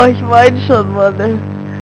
weinen